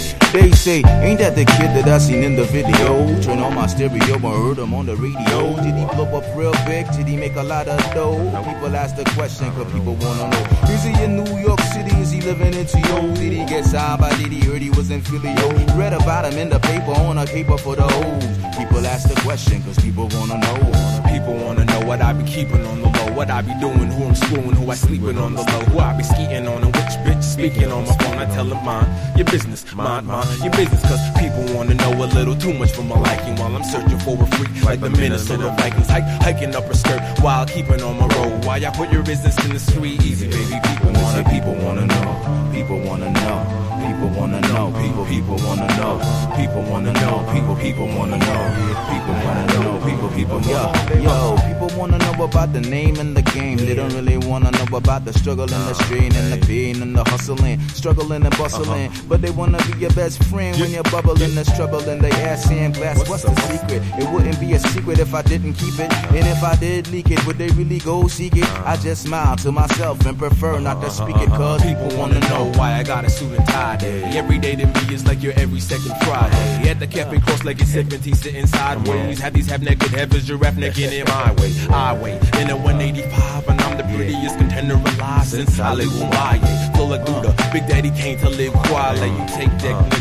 00’S HIPHOP# JAZZY HIPHOP